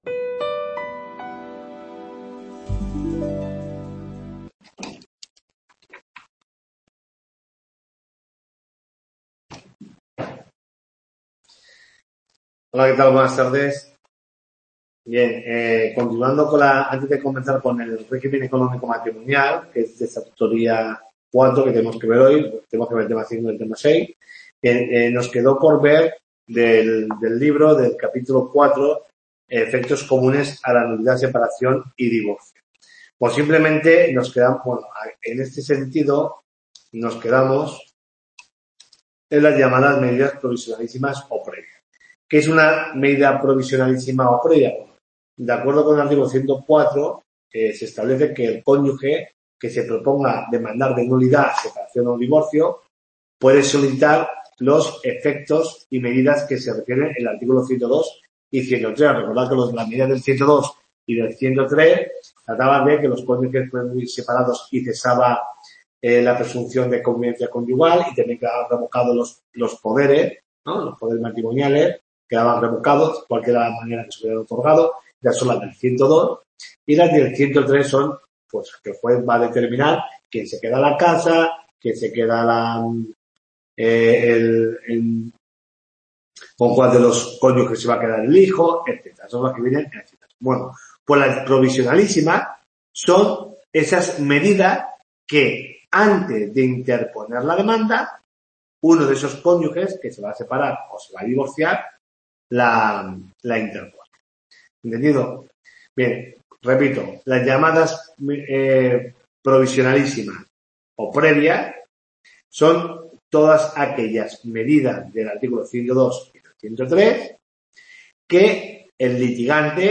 TUTORIA 4